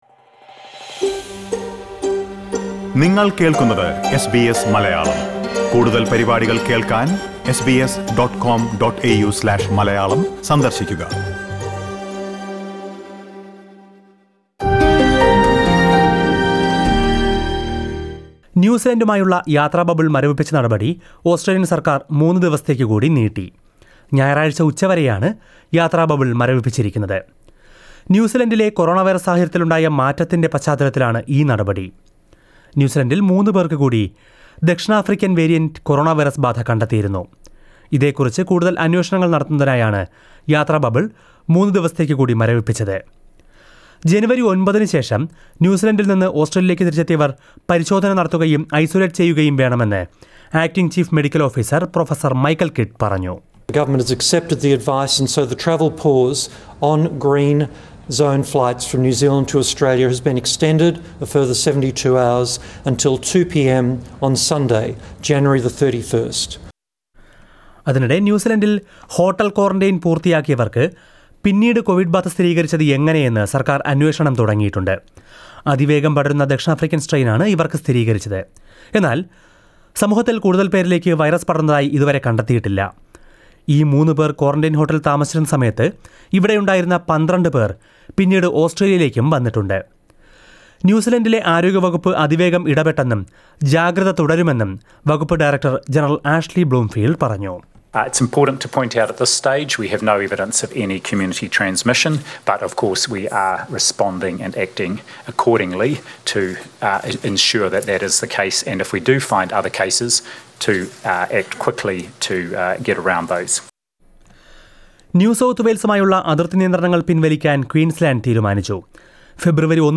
SBS Malayalam COVID-19 news update.